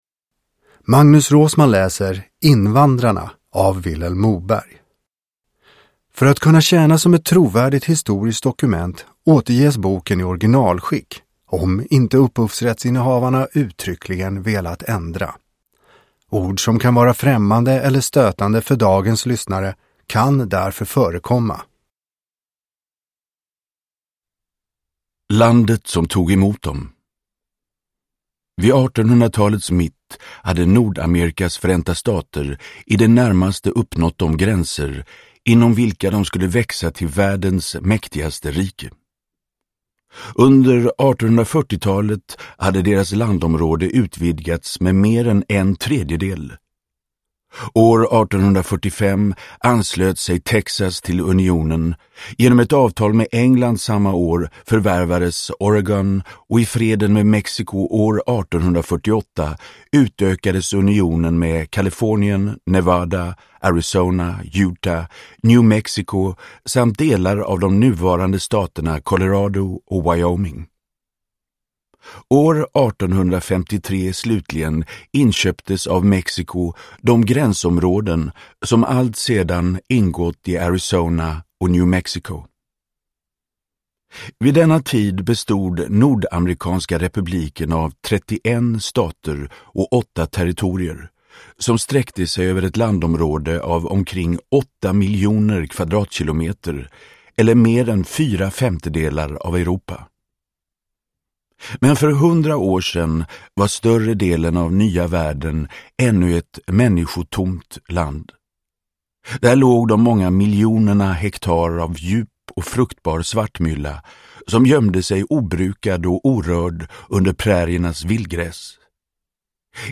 Invandrarna – Ljudbok – Laddas ner
Uppläsare: Magnus Roosmann